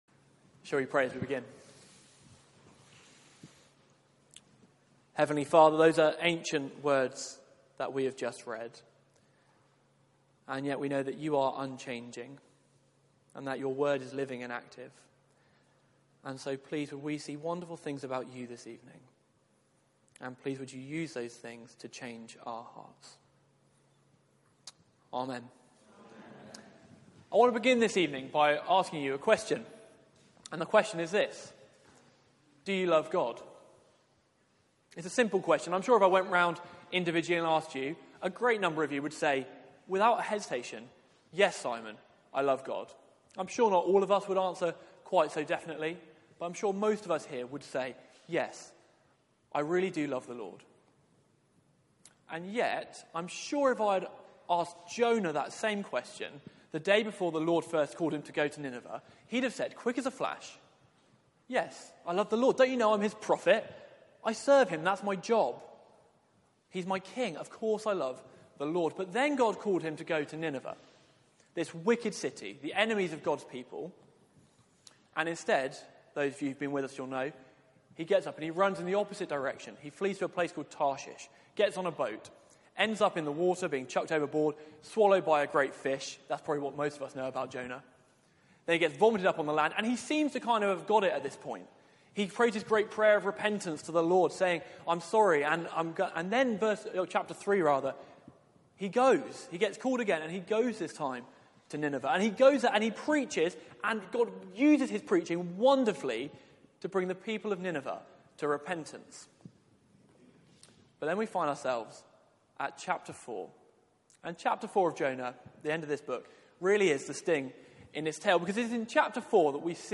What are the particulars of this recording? Media for 6:30pm Service on Sun 13th May 2018 18:30 Speaker